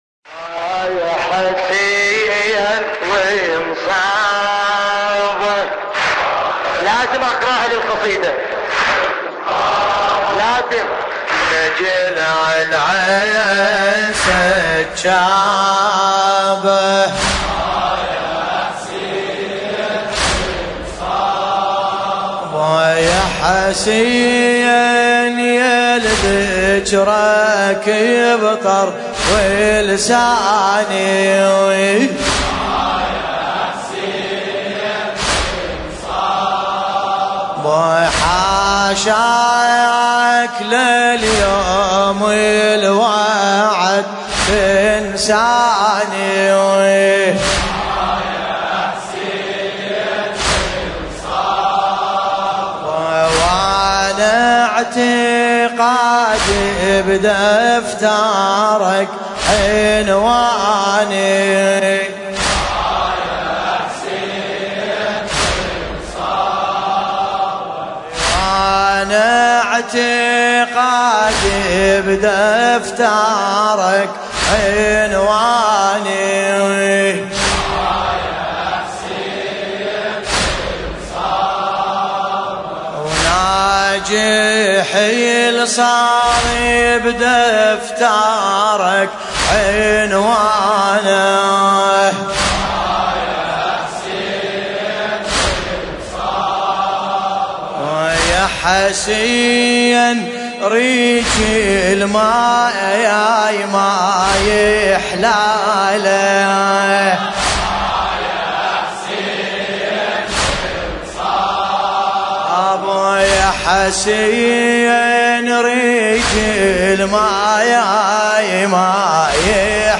المناسبة : رثاء الامام الحسين (ع)
الزمن : ليلة 22 محرم 1440 هـ